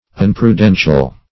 Search Result for " unprudential" : The Collaborative International Dictionary of English v.0.48: Unprudential \Un`pru*den"tial\, a. Imprudent.
unprudential.mp3